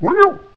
snd_bigcar_yelp.ogg